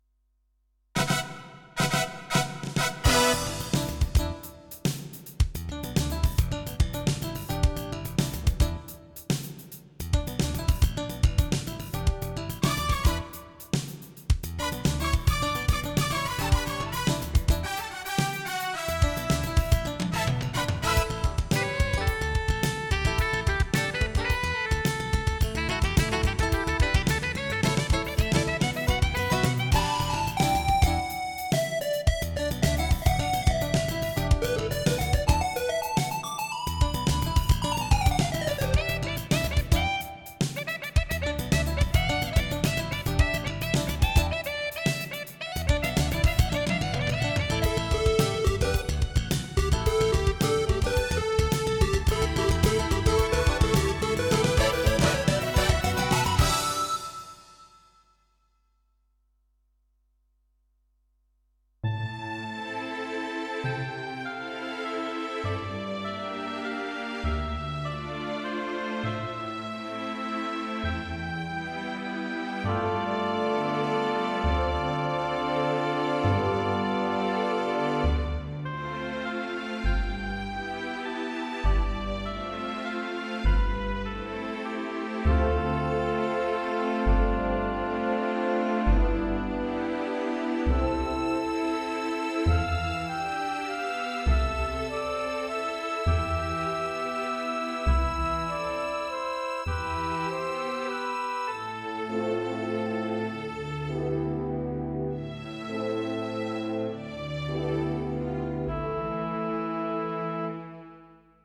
Roland JV-1080 Synthesizer